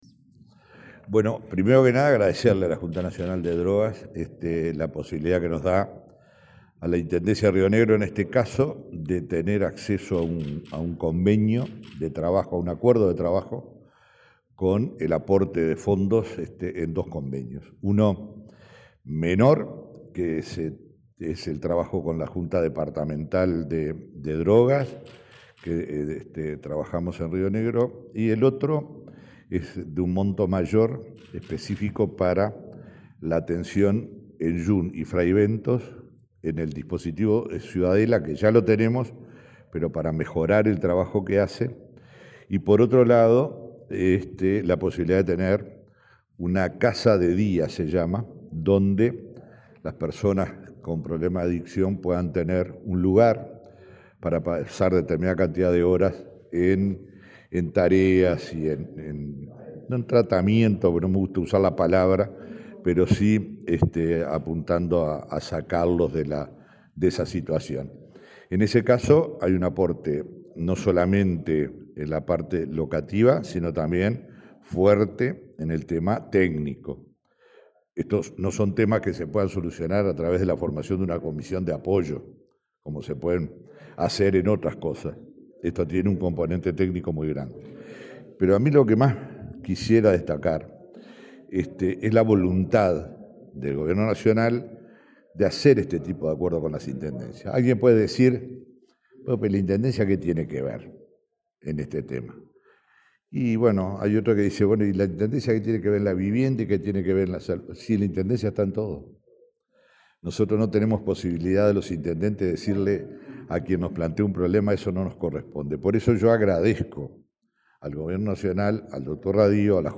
Entrevista al intendente de Río Negro, Omar Lafluf
El intendente de Río Negro, Omar Lafluf, dialogó con Comunicación Presidencial, este lunes 26 en la Torre Ejecutiva, luego de firmar dos convenios con